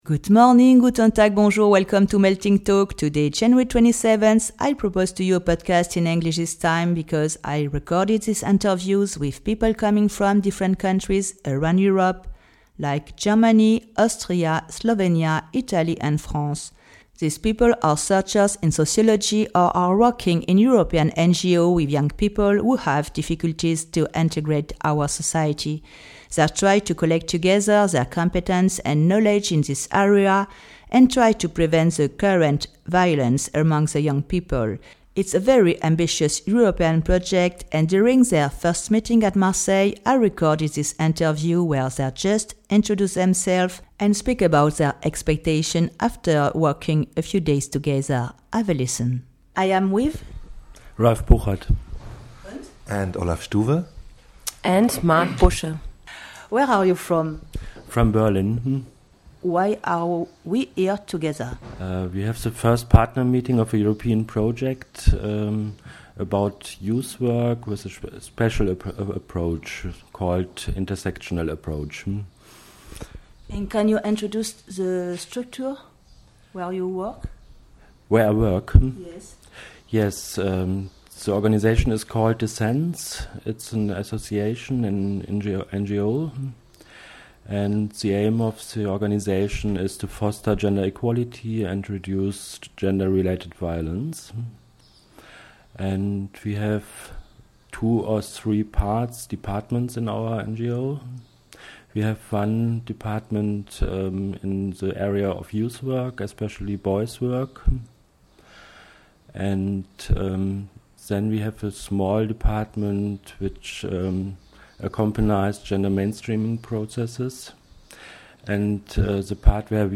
In this podcast (part 1) they introduce themselves and speak about their expectations through this project which is working two years long with regular meetings planned in each countries : After Marseille (Fr), they a